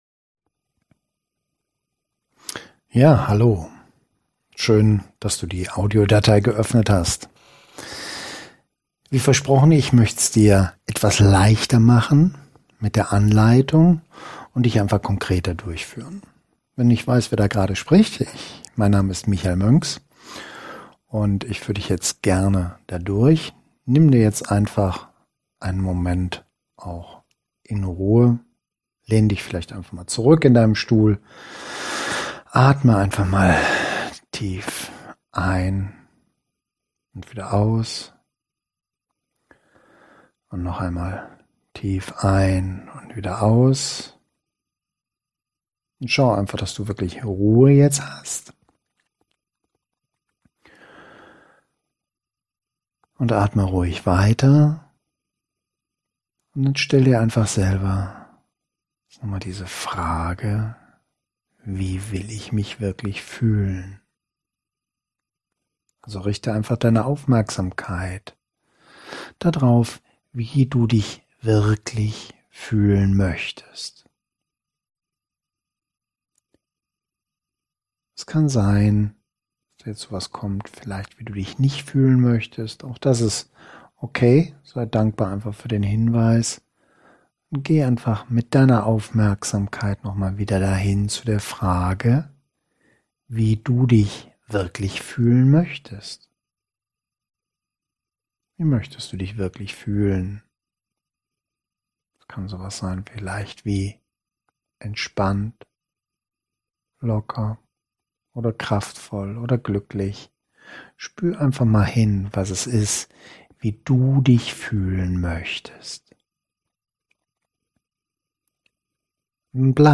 Um es dir etwas leichter zu machen, findest du hier eine erweiterte  Anleitung als Audiodatei: